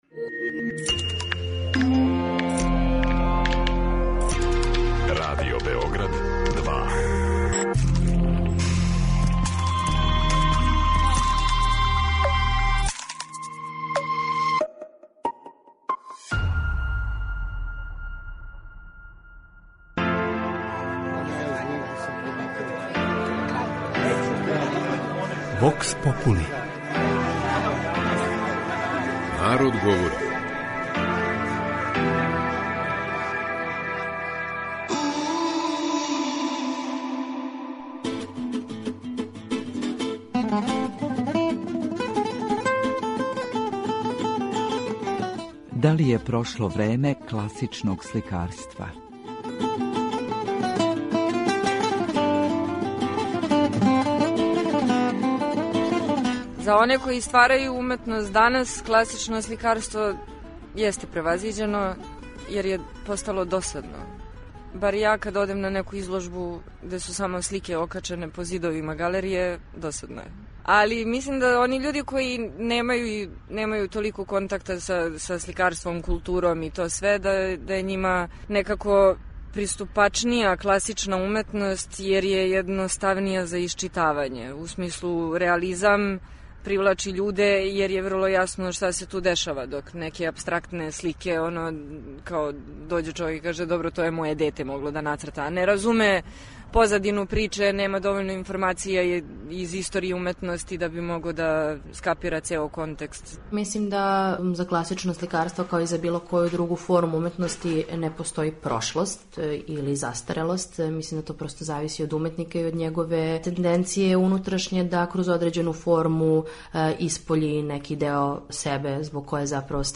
Може ли класично сликарство и у 21. веку задовољити наше естетске критеријуме и потребе. Питали смо наше суграђане „Да ли је прошло време класичног сликарства?".
Вокс попули